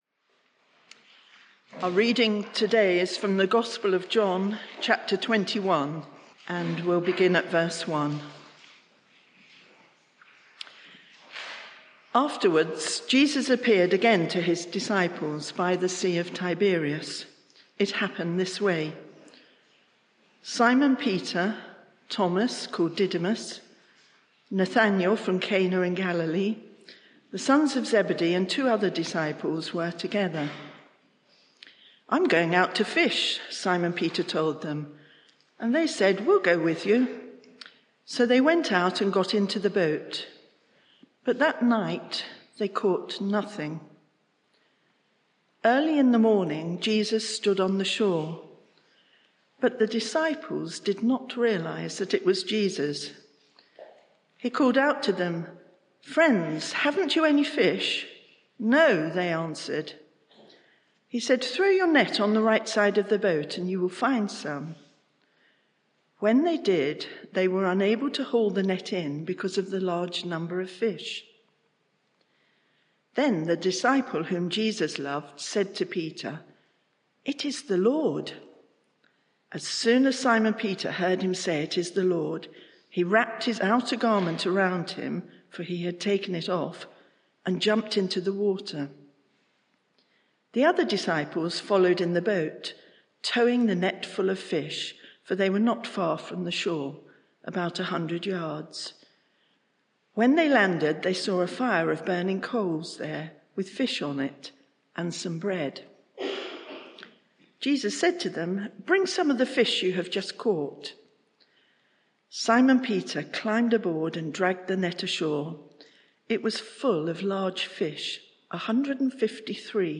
Media for 11am Service on Sun 23rd Apr 2023 11:00 Speaker
Sermon (audio)